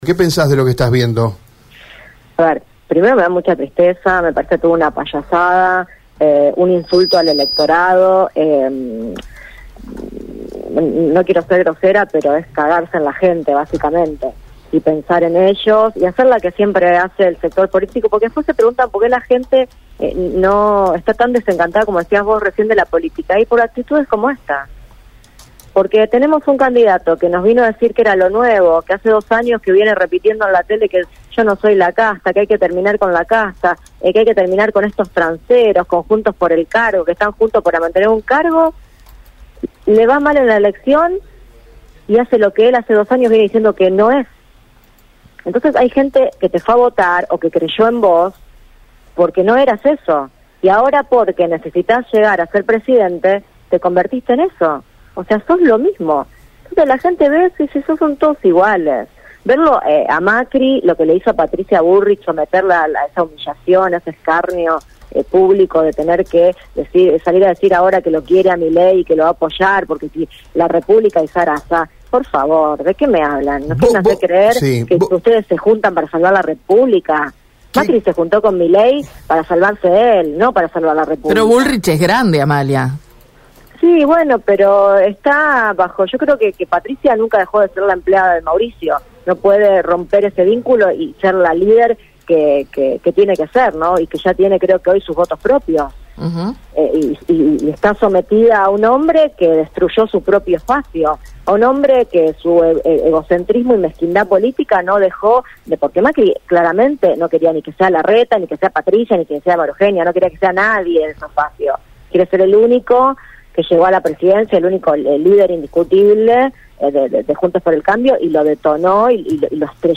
En Radio EME, la diputada del bloque Somos Vida se despachó sin rodeos, como es su estilo, sobre la sorpresiva coalición que se anunció este miércoles entre Milei y la candidata de Juntos por el Cambio, Patricia Bullrich.